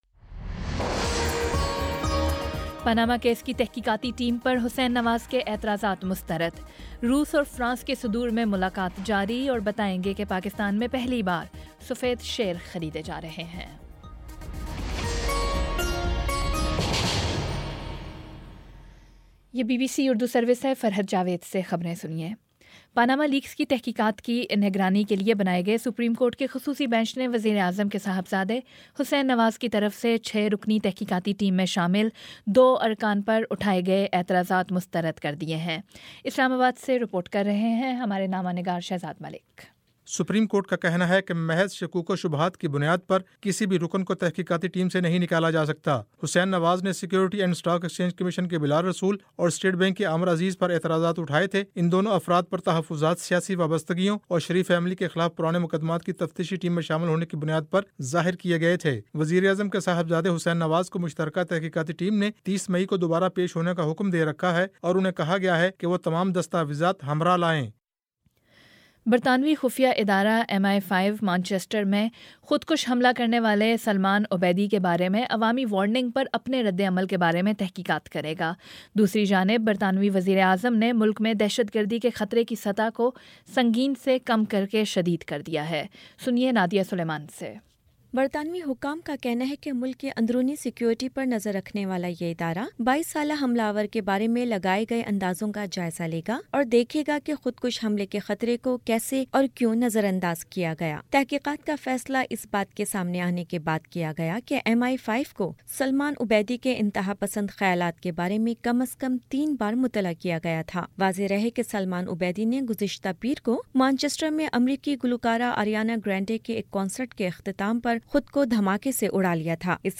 مئی 29 : شام چھ بجے کا نیوز بُلیٹن